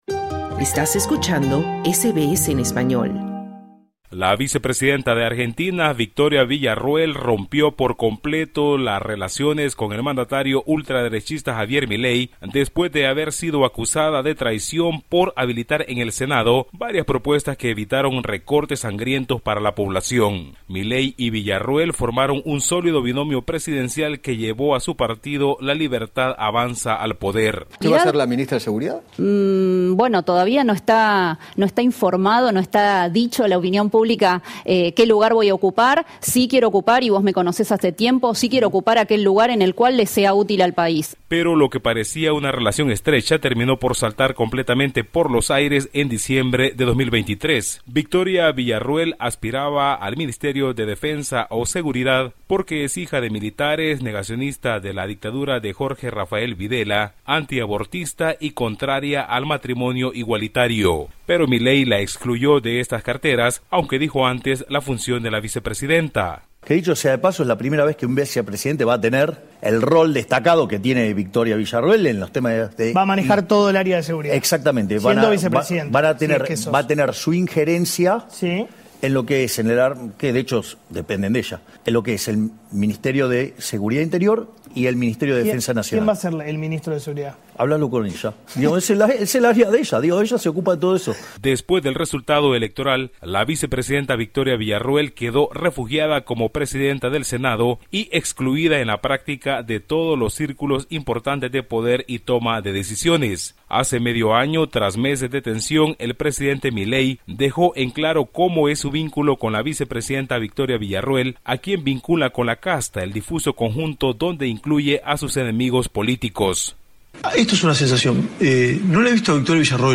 Javier Milei y Victoria Villarruel rompieron relaciones tras diferencias en el Senado. El presidente la acusó de traición y crece la tensión dentro del Ejecutivo. Informe de nuestro corresponsal en Latinoamérica